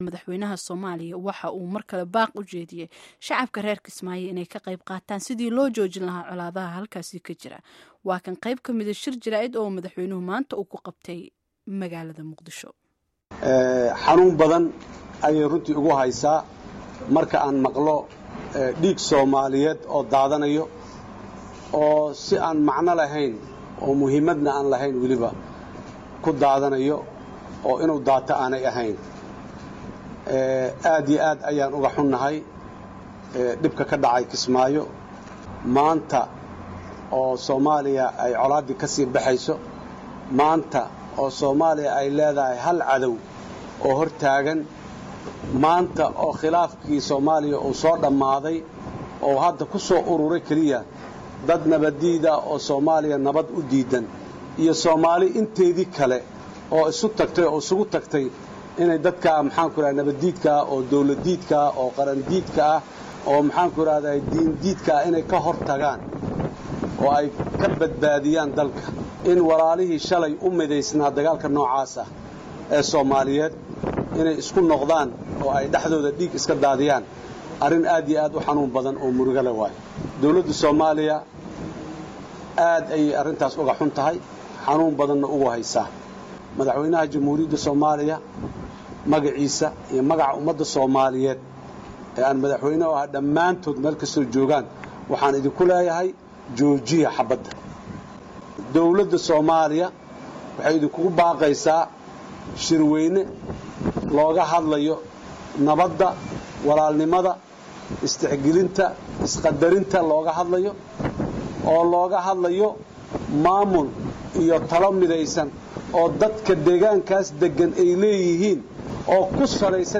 Hadalka Madaxweynaha Soomaaliya